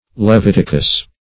leviticus - definition of leviticus - synonyms, pronunciation, spelling from Free Dictionary
Leviticus \Le*vit"i*cus\ (-[i^]*k[u^]s), n. [See Levitical.]